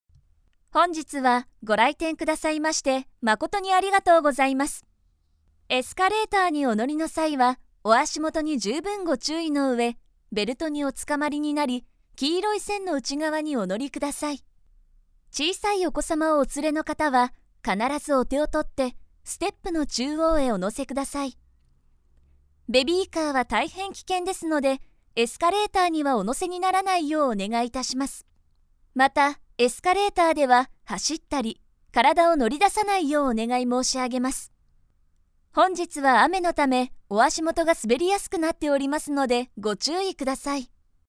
I am capable of bright and energetic narration, but I am also good at expressing things in depth.
– Narration –
Broadcasting in department stores